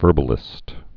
(vûrbə-lĭst)